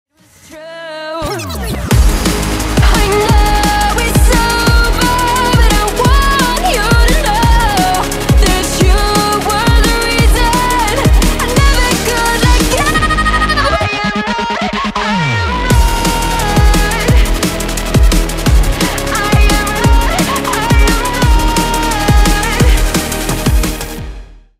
Reese Bassとは？重低音と空間の広がりが特徴の定番サウンド
Reese Bass（リースベース）とは、重く捻るような低音と空間を包み込むような広がりが特徴的なベースサウンドです。
▼Reese Bass サウンドサンプル
さらに音に厚みと広がりを加えるために、「Unison Voices」を7〜8程度に設定し、「Detune」は30%ほどと、少し強めに設定します。
これで一気に攻撃的なサウンドになります。
ReeseBass.mp3